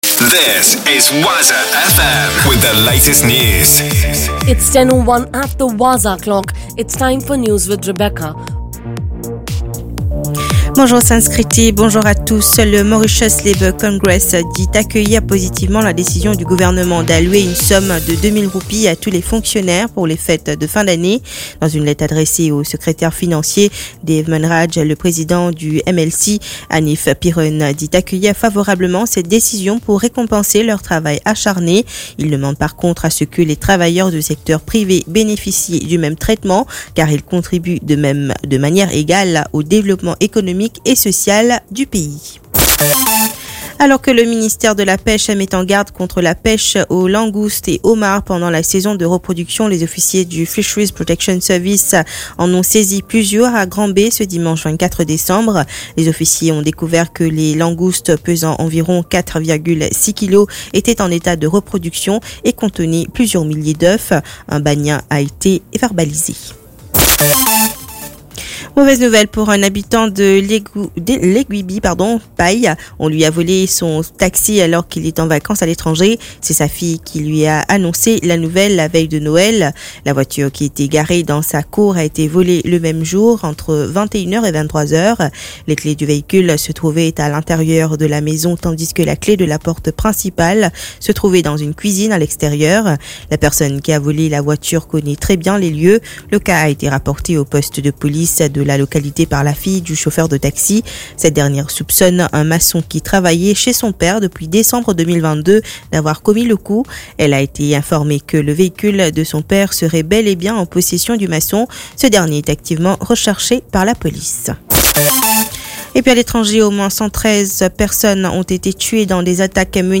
NEWS 10h - 26.12.23